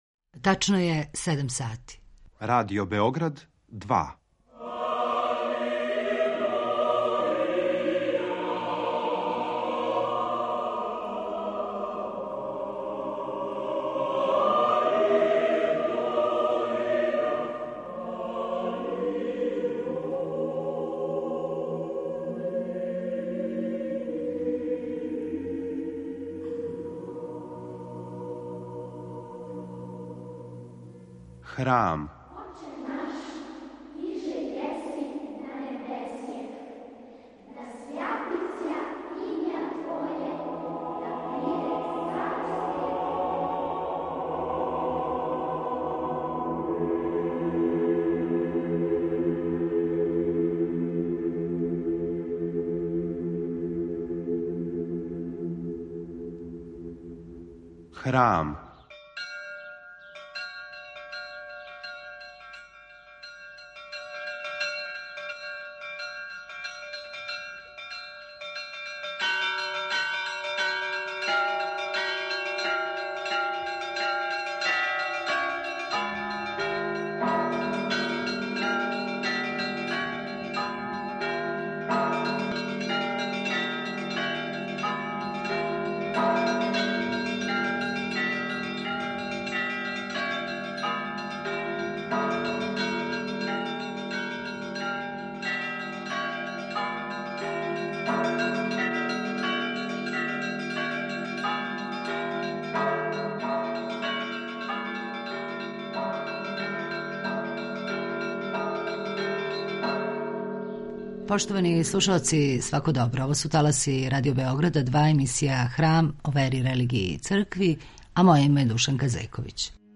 (Из Жичке беседе Светог Саве о правој вери) Поводом стогодишњице почетка рада Православног богословског факултета и осамсто годишњице Беседе о правој вери, коју је под сводовима Жичке Архиепископије произнео Свети Сава, у организацији Православног богословског факултета Универзитета у Београду и Храма Светог Саве, 15. и 16. новембра 2021. организован је научни скуп под називом - Богословље код Срба: Изазови и перспективе.